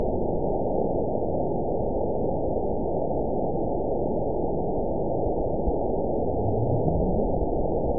event 920413 date 03/23/24 time 21:57:59 GMT (1 year, 1 month ago) score 9.49 location TSS-AB04 detected by nrw target species NRW annotations +NRW Spectrogram: Frequency (kHz) vs. Time (s) audio not available .wav